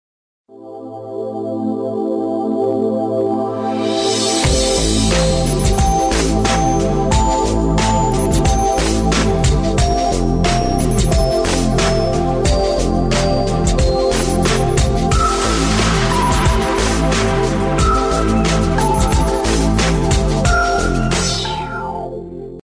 آهنگ نیمه هیجانی برای موبایل